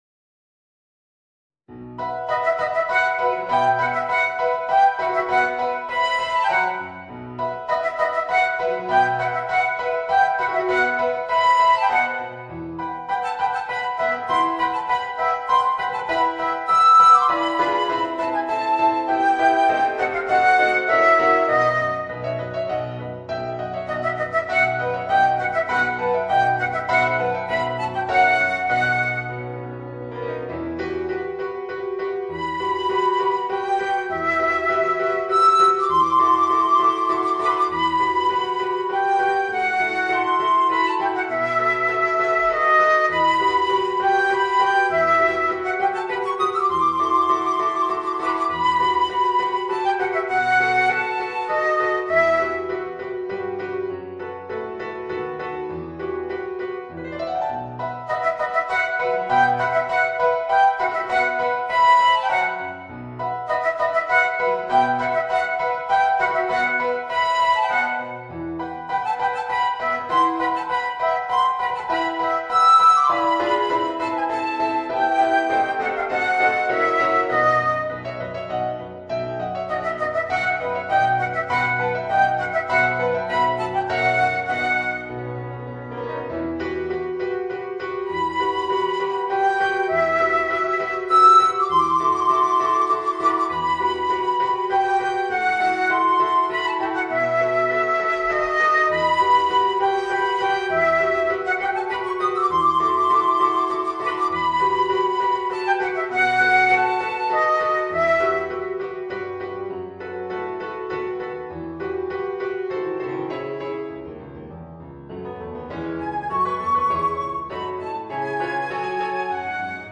Voicing: Flute and Piano